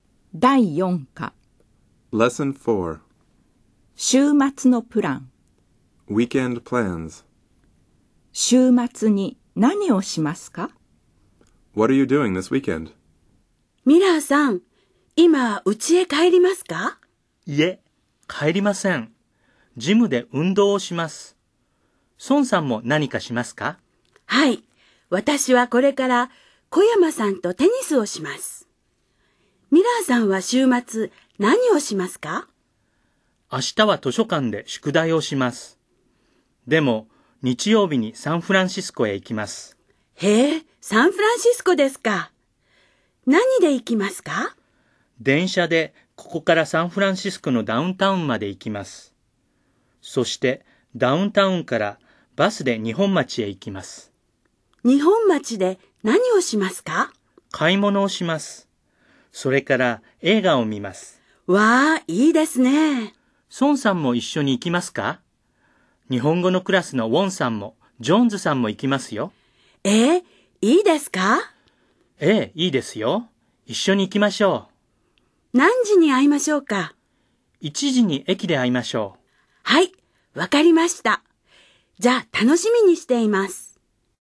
dialog4.mp3